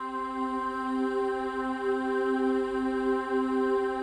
PAD 50-4.wav